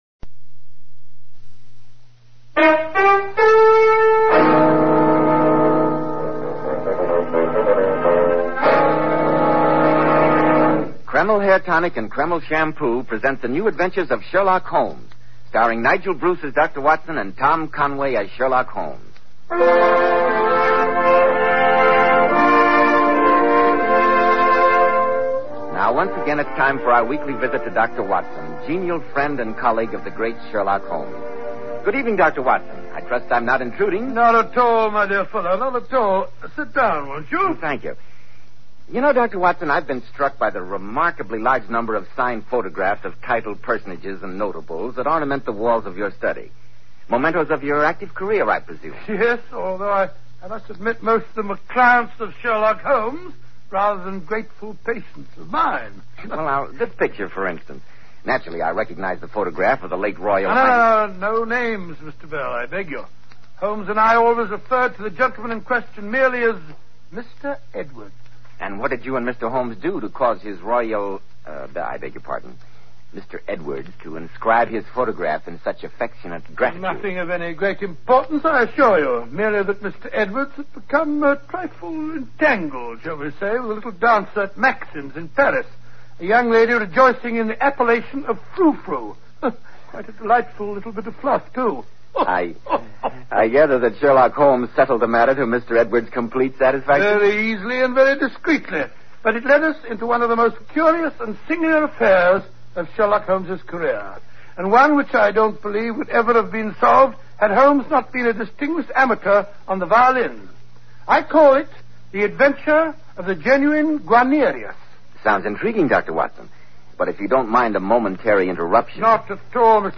Radio Show Drama with Sherlock Holmes - The Genuine Guarnarius 1946